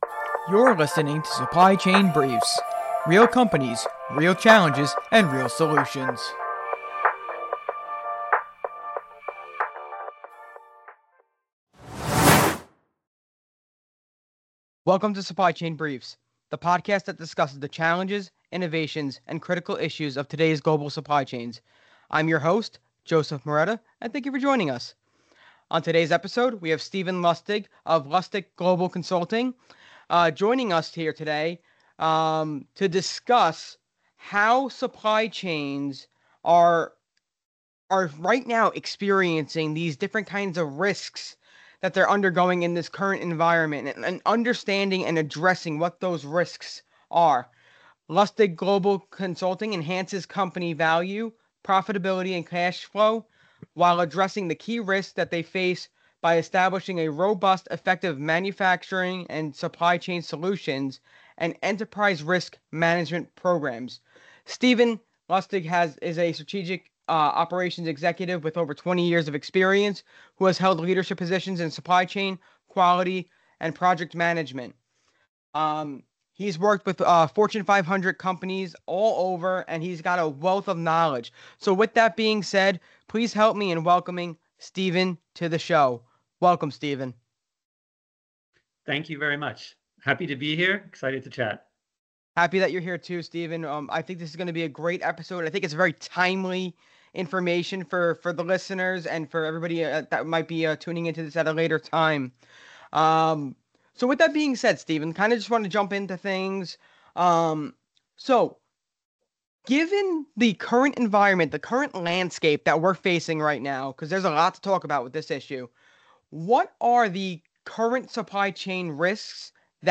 ASCM NYCLI Supply Chain Briefs presents Supply Chain Technology Panel Discussion – ASCM NYC-LI presents Supply Chain Briefs